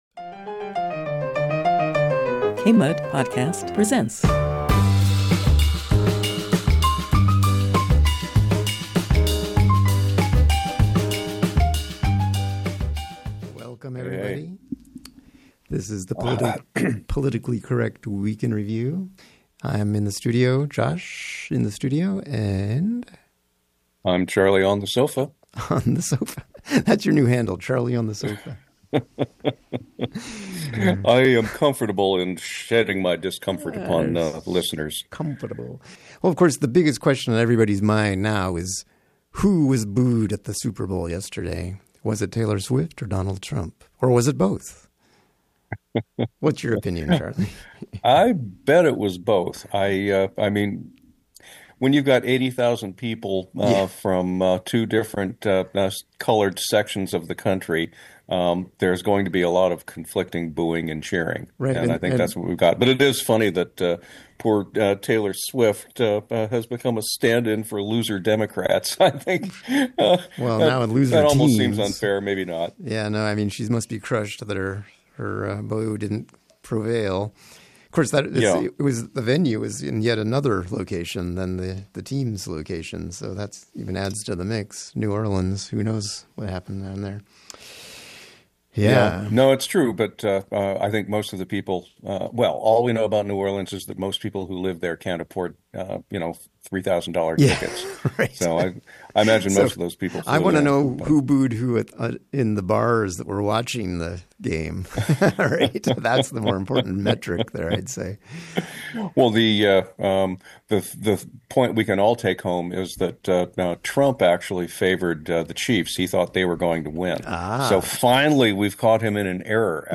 Callers weigh in on fairness in politics, education vs. tech, COVID conspiracies, and the influence of money on power.